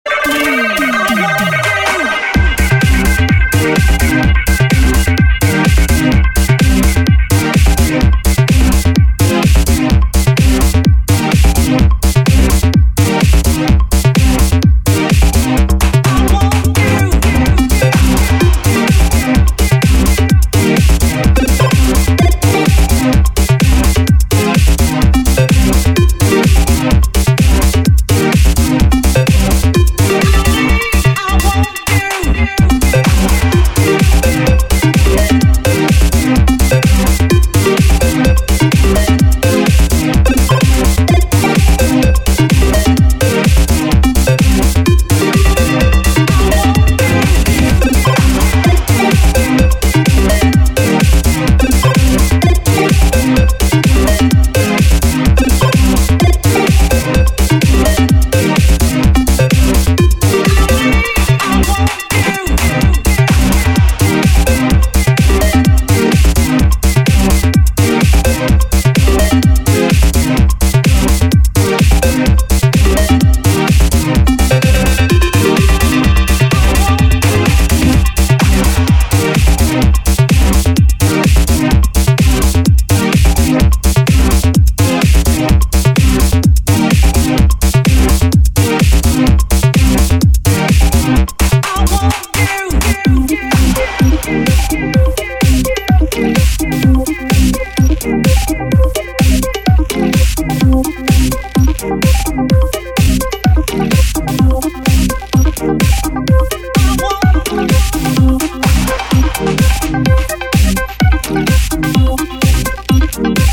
Друзья это тоже (Electro house)